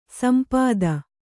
♪ sampāda